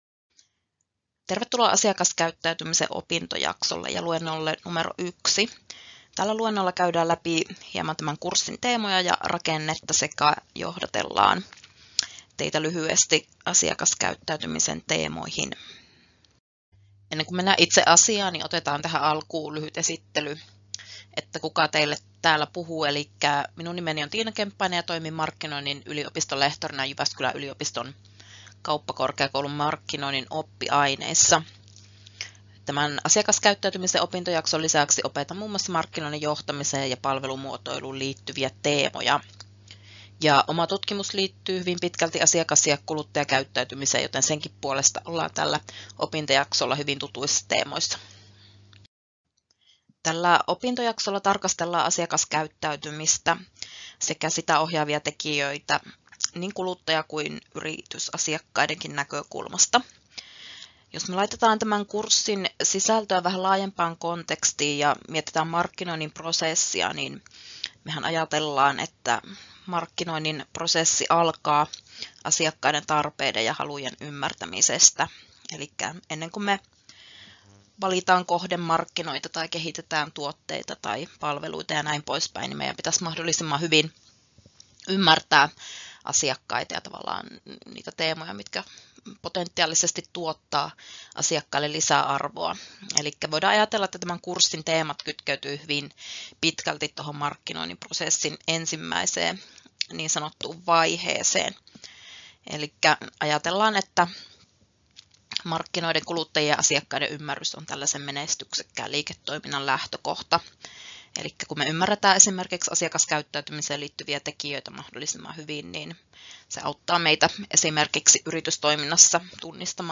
Luento 1: Johdanto asiakaskäyttäytymisen opintojaksolle — Moniviestin